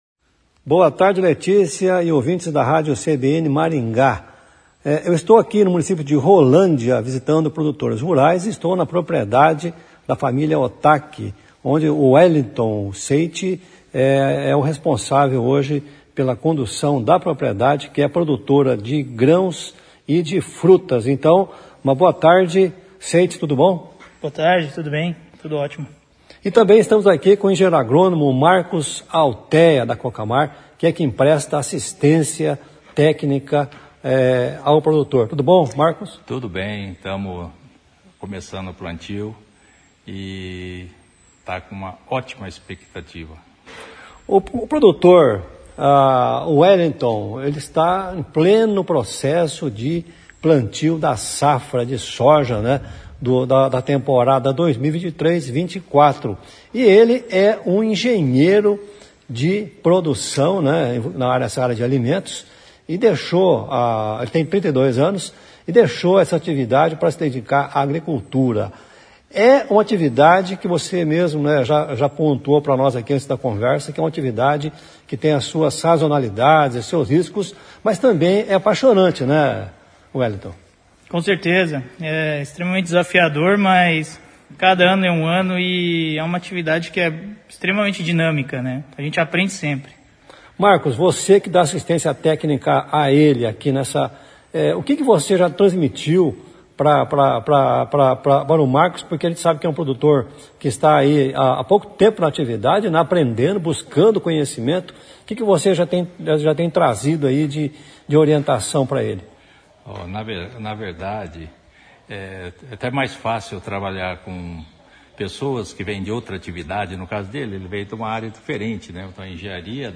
O CBN Rural desta sexta-feira conversou no município de Rolândia (PR)